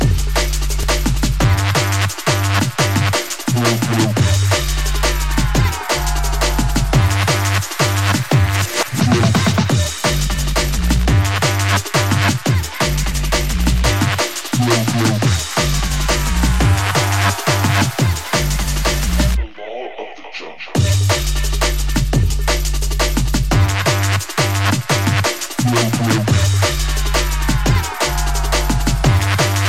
TOP >Vinyl >Drum & Bass / Jungle
TOP > Jump Up / Drum Step